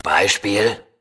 Fallout: Audiodialoge